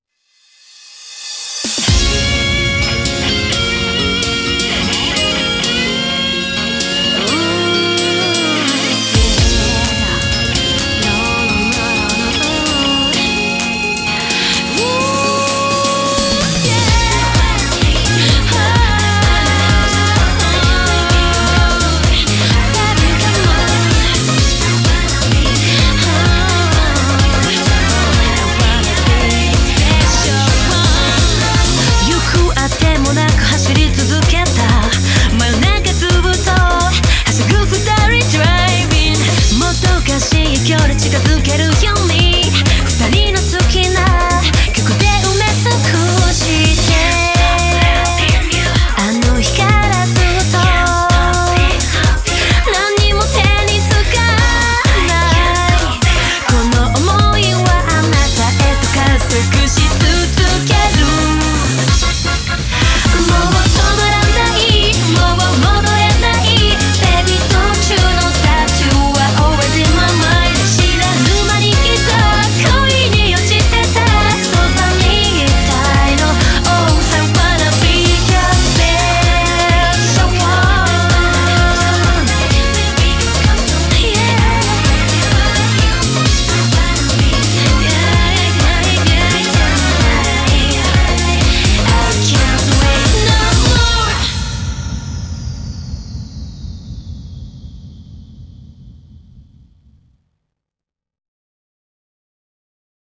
BPM128